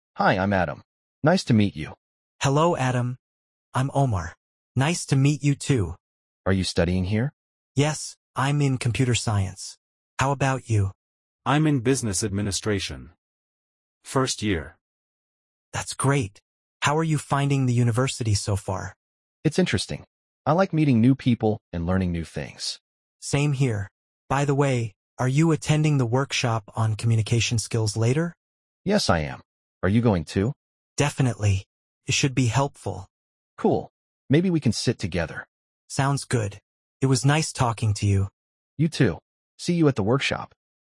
Active Listening Practice